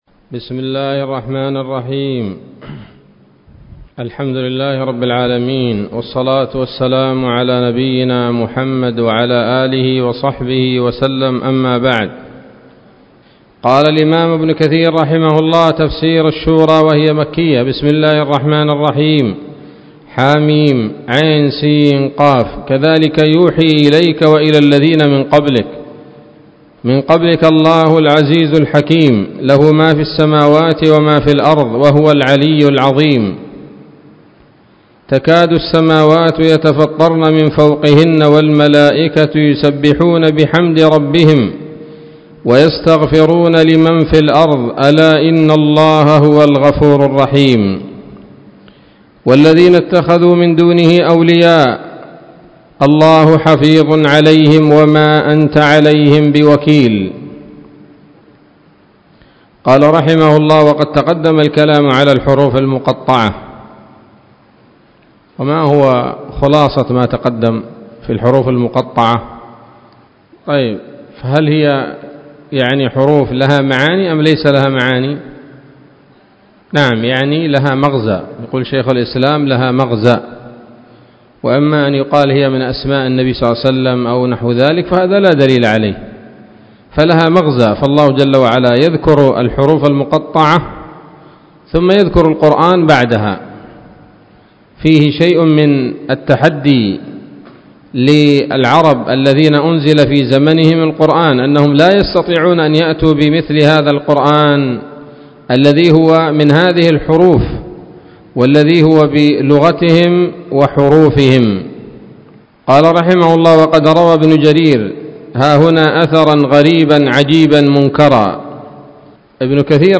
الدرس الأول من سورة الشورى من تفسير ابن كثير رحمه الله تعالى